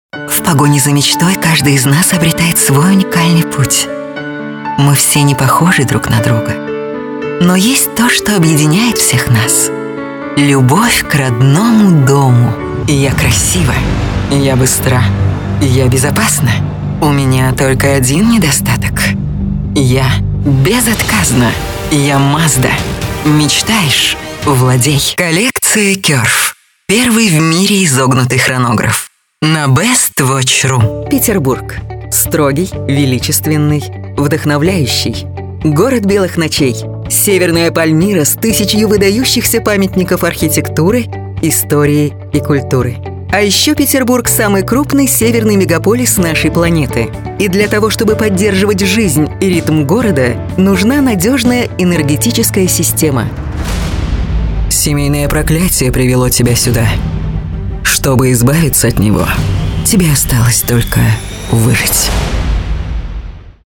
Дикторские голоса (Озвучка)
Женские